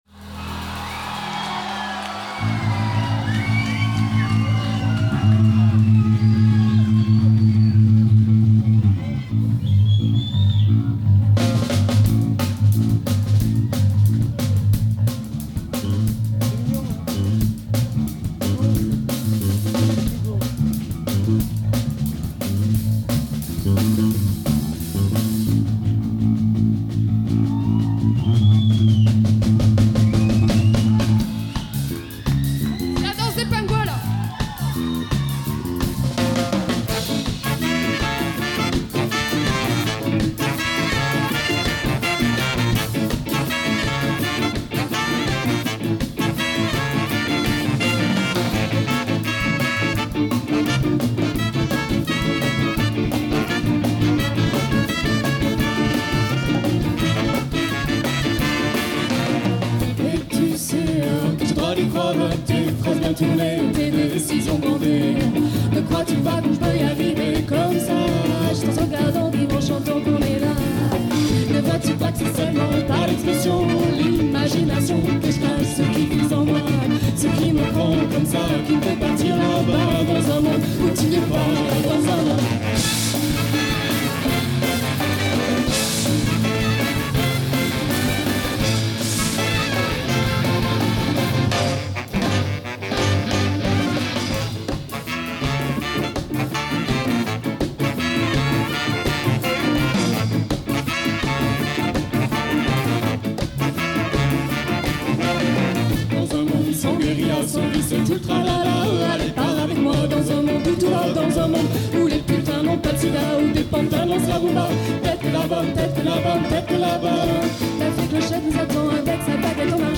enregistrement en live au Havana en Février 2004